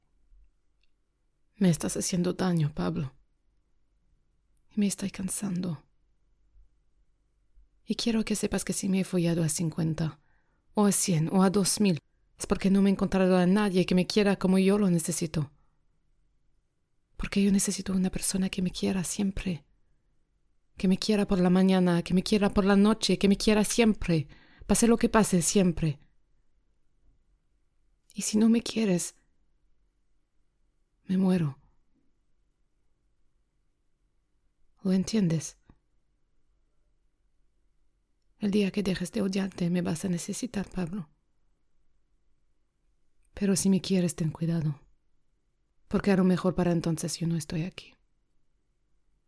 Voix off
5 - 40 ans - Mezzo-soprano